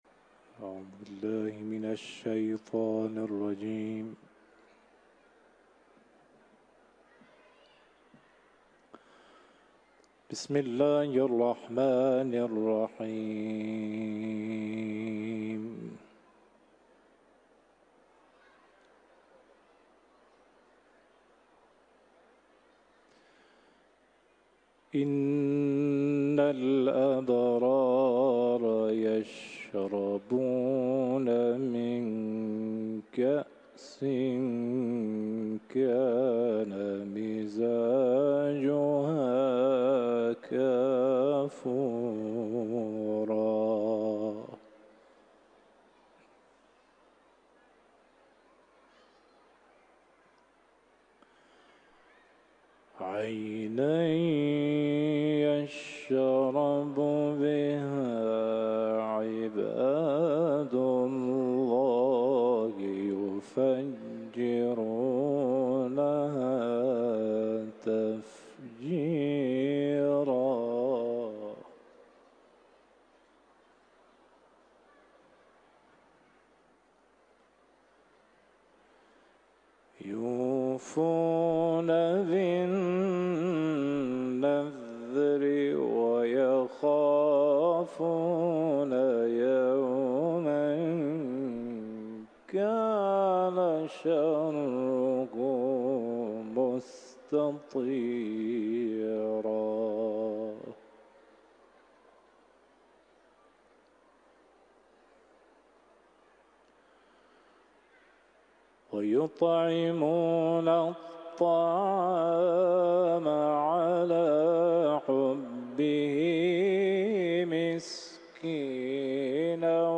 تلاوت قرآن
سوره انسان ، حرم مطهر رضوی